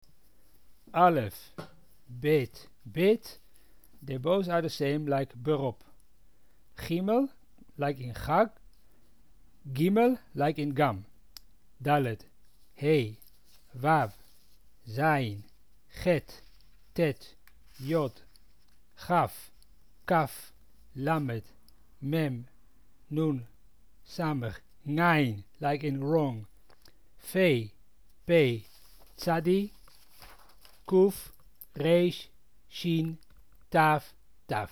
This page describes the rules of pronunciation, as used by the Amsterdam Portuguese community.